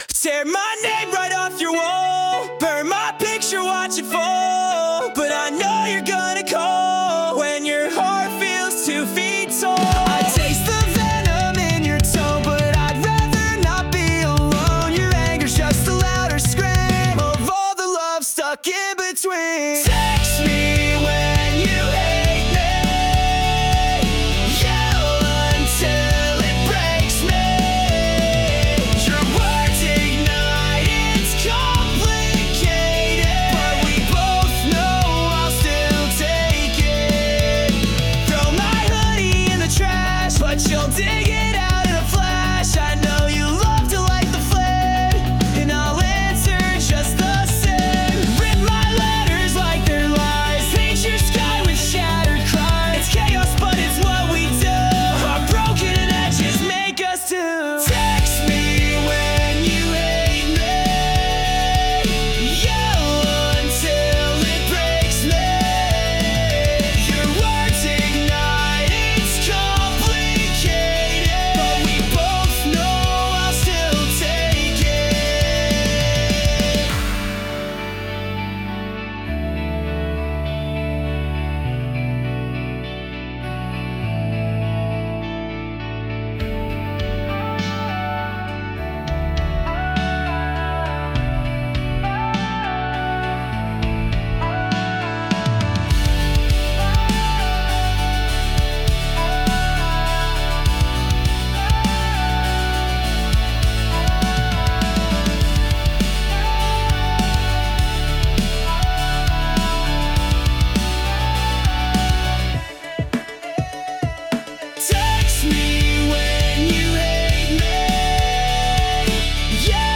Genre: Pop Punk Mood: Emotional